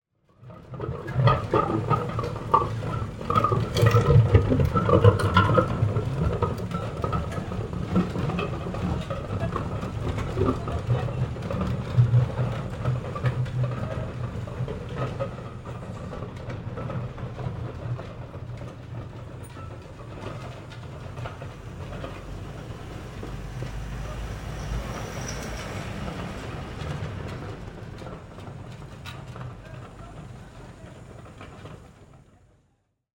Carrreta de bueyes en el asfalto GUANACASTE